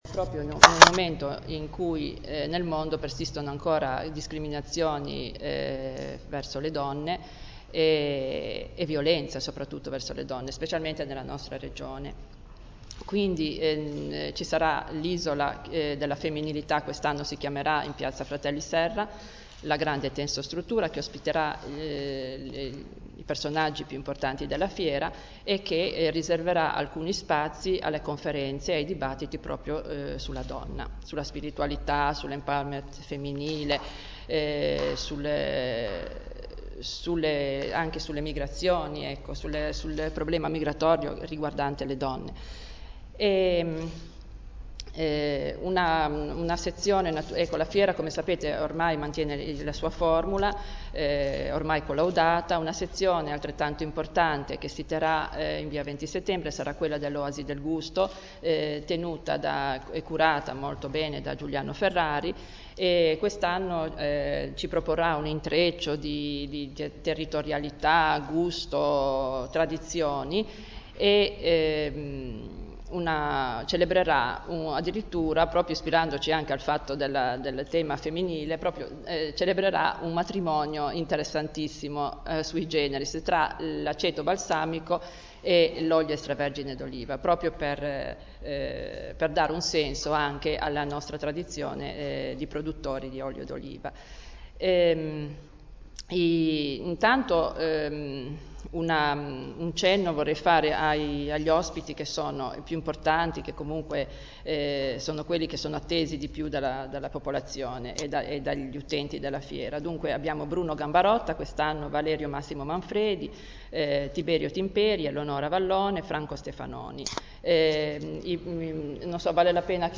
Conferenza_Stampa_fiera_del_Libro_2012.MP3